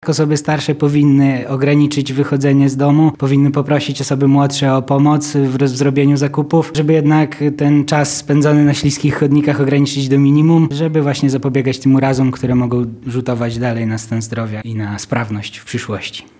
ratownik medyczny z Lublina